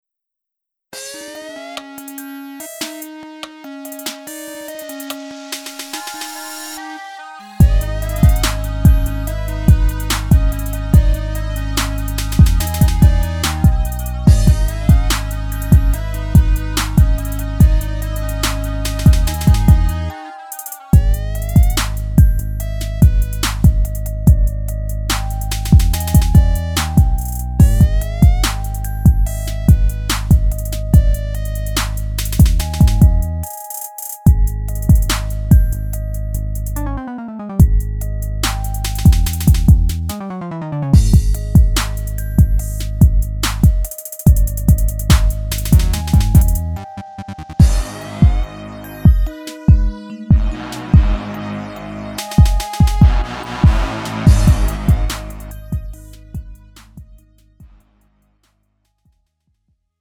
음정 원키 2:39
장르 가요 구분 Lite MR